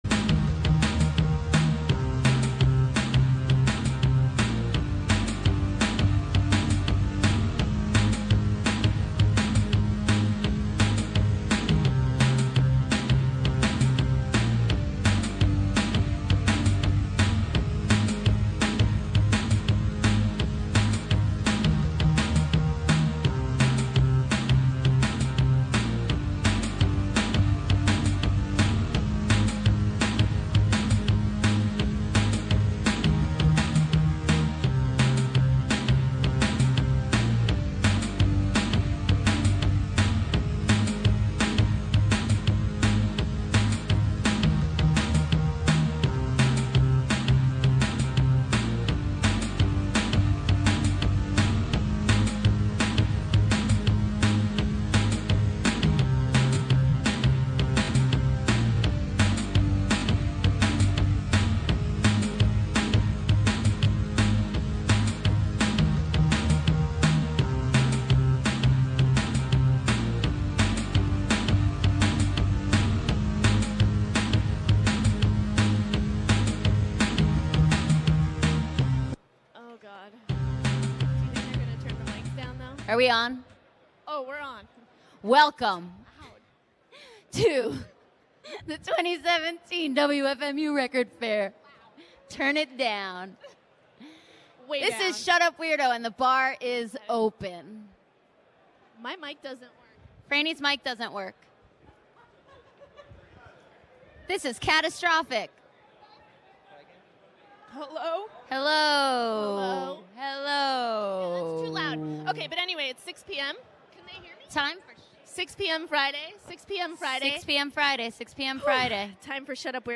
Live at the WFMU Record Fair from Apr 28, 2017